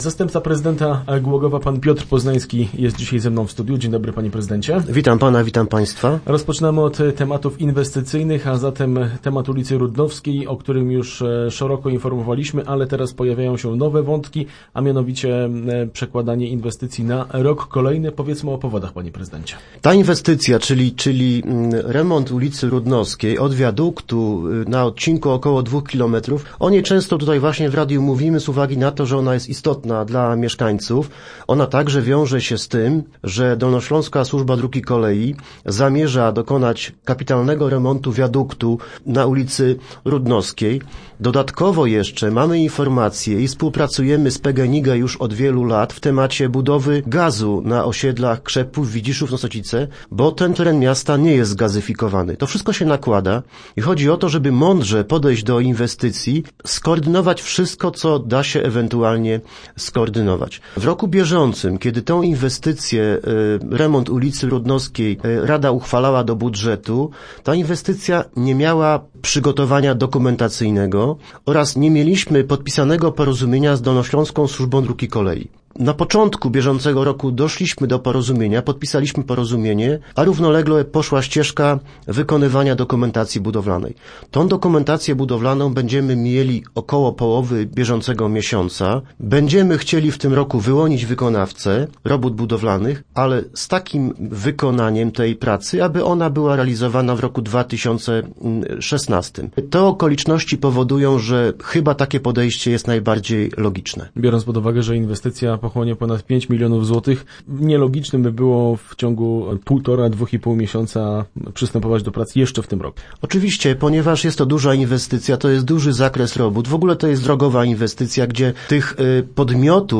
O tym w radiowym studiu mówił zastępca prezydenta miasta Piotr Poznański.